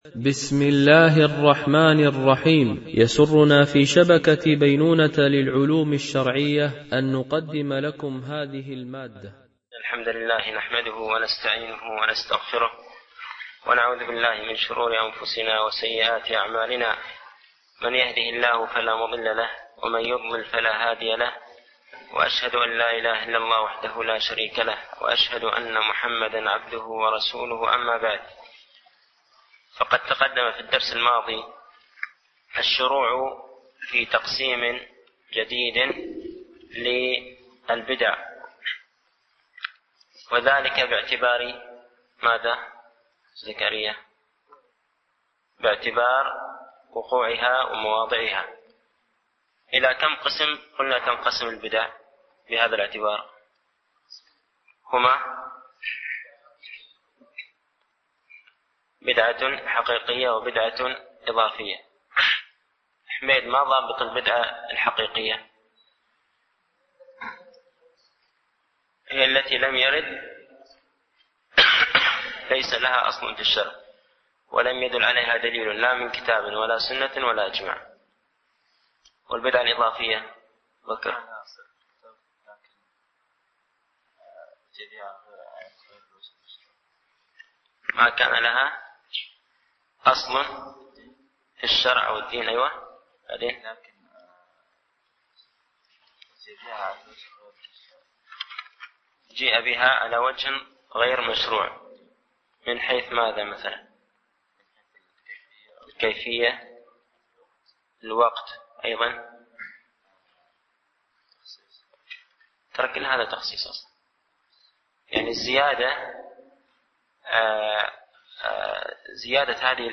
) الألبوم: شبكة بينونة للعلوم الشرعية التتبع: 132 المدة: 30:04 دقائق (6.92 م.بايت) التنسيق: MP3 Mono 22kHz 32Kbps (CBR)